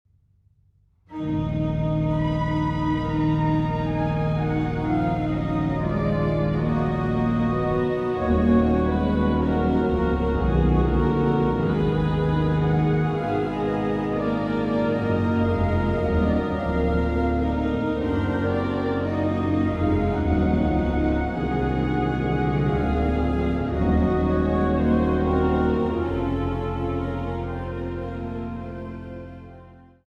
Choral in Alto